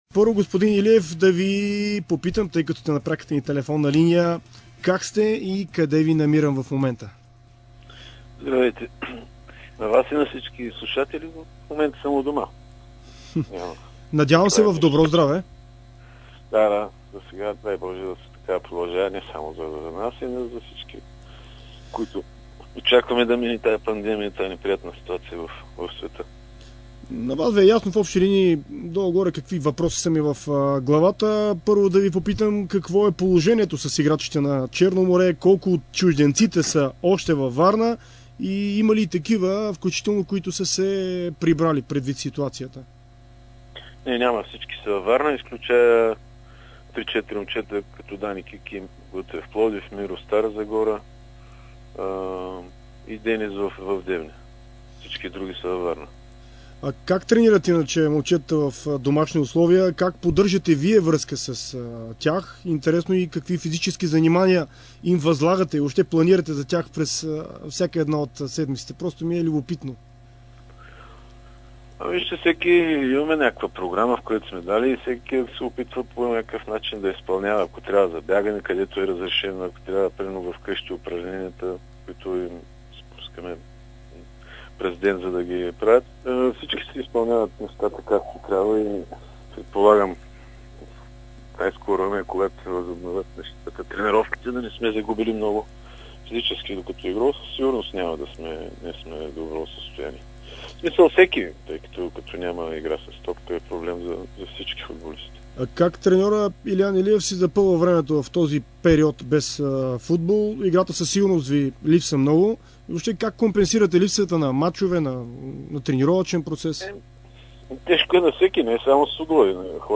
Старши треньорът на Черно море Илиан Илиев говори в интервю за dsport и Дарик радио за това колко време най-малко ще е необходимо за подготовка на играчите преди да се продължи евентуално първенството, за да навлязат в някаква форма и как се готвят те в момента. Той сподели, че трябва да бъде премислена голямата почивка през зимната пауза в първенството.